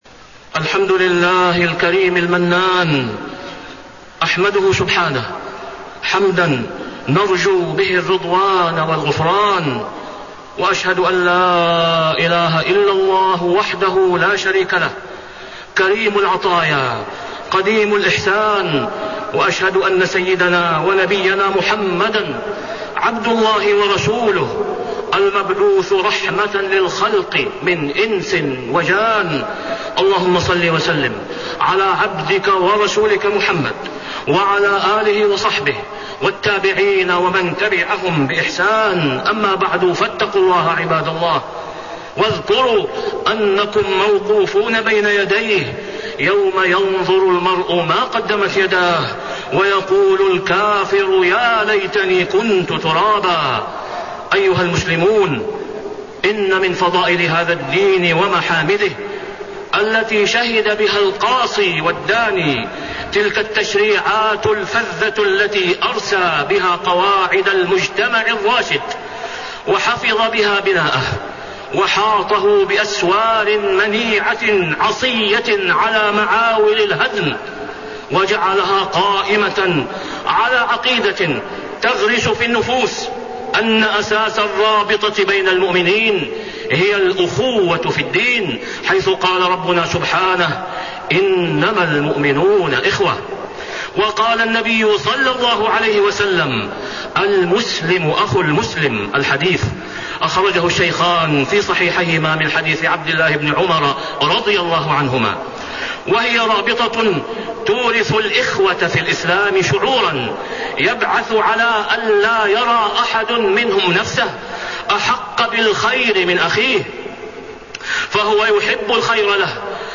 تاريخ النشر ٢٠ ربيع الأول ١٤٣٤ هـ المكان: المسجد الحرام الشيخ: فضيلة الشيخ د. أسامة بن عبدالله خياط فضيلة الشيخ د. أسامة بن عبدالله خياط حرمة التجسس على المسلمين